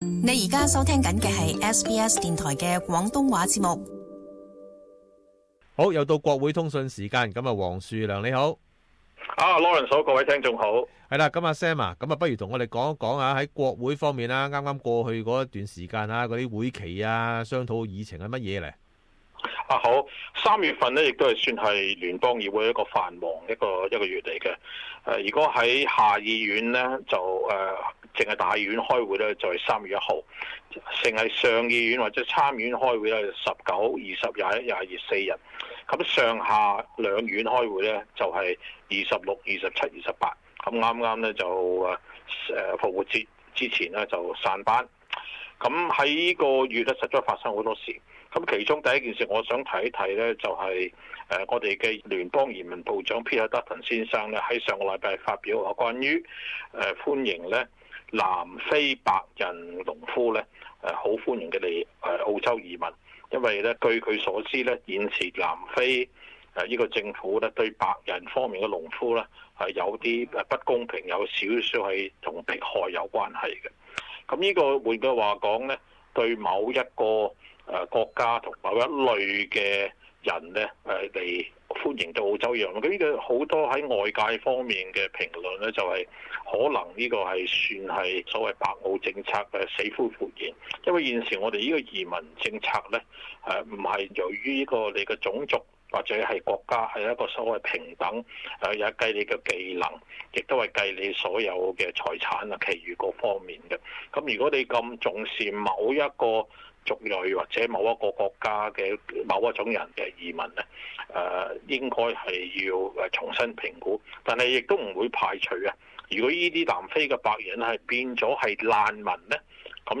【国会通讯】移民长达顿言论引起社会关注